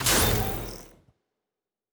Door 8 Open.wav